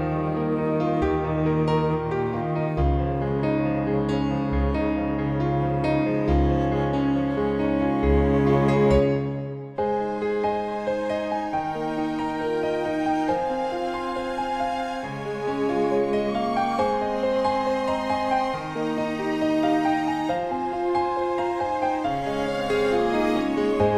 Up 2 Semitones For Female